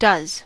does-todo: Wikimedia Commons US English Pronunciations
En-us-does-todo.WAV